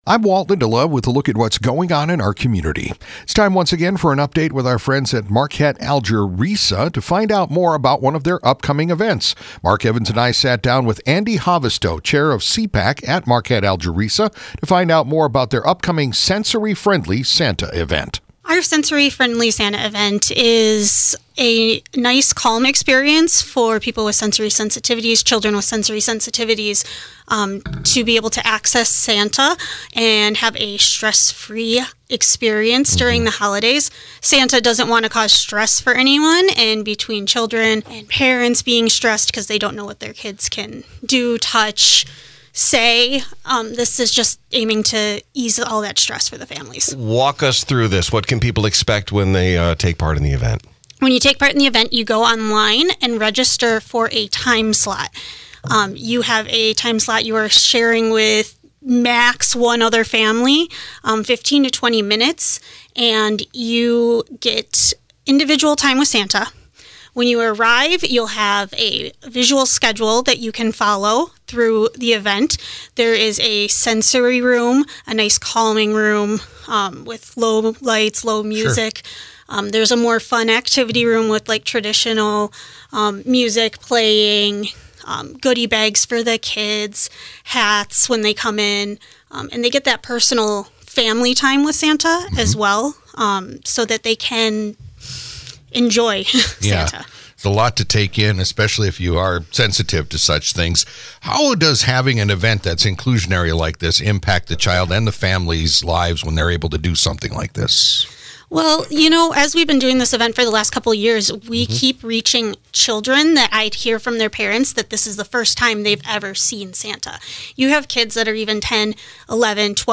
spoke about the event